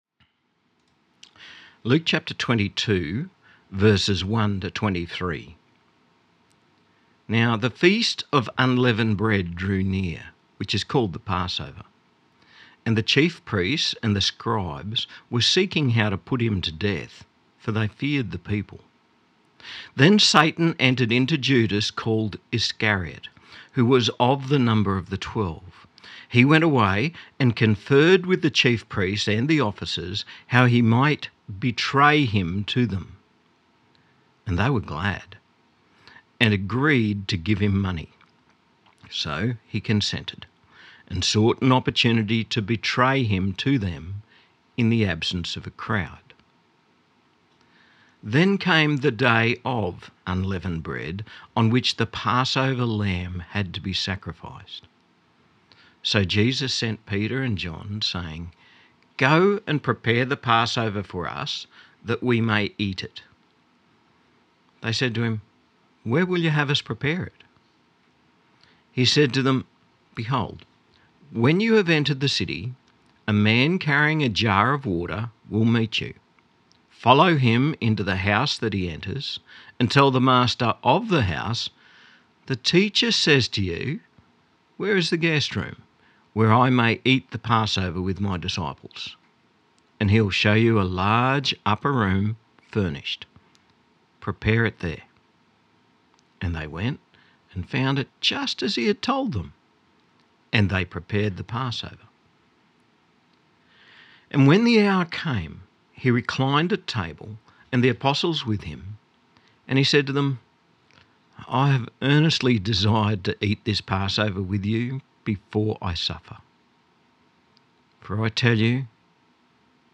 Weekly messages of the Bush Disciples evangelical Christian Church in the St George QLD district.